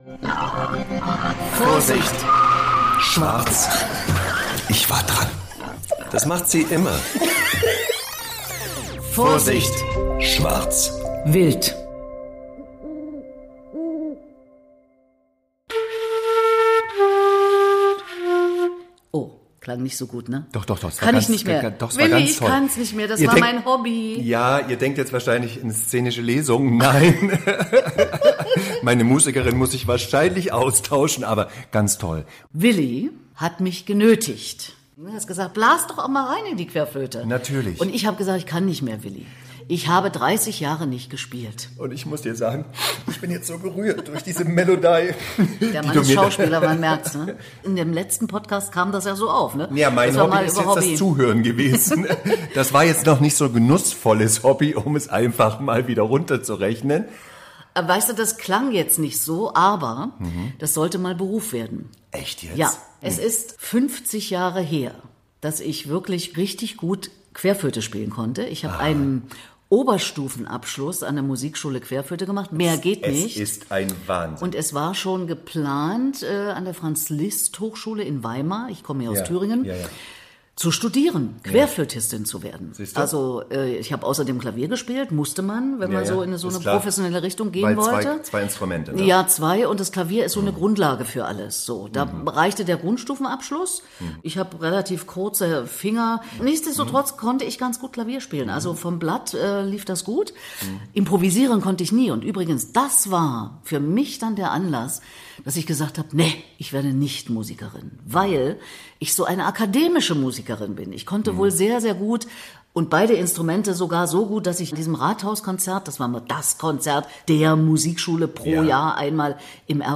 Außergewöhnlicherweise startet diese Episode mit live-Musik.